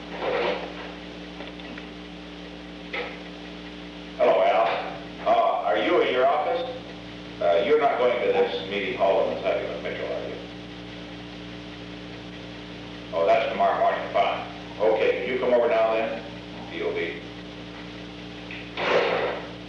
On January 13, 1972, President Richard M. Nixon and Alexander M. Haig, Jr. met in the President's office in the Old Executive Office Building at an unknown time between 3:52 pm and 3:55 pm. The Old Executive Office Building taping system captured this recording, which is known as Conversation 314-007 of the White House Tapes.